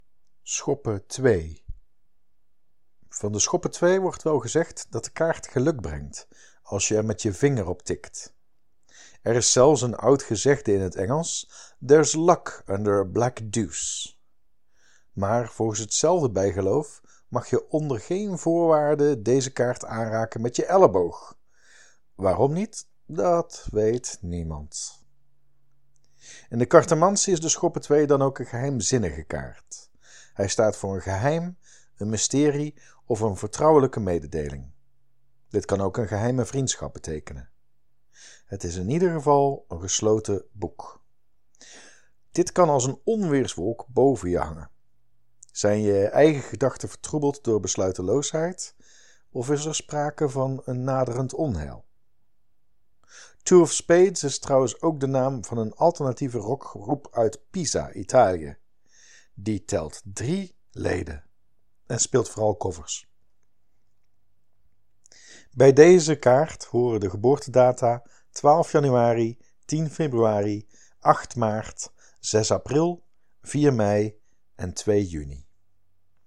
06.02b-Schoppen-twee-toelichting.mp3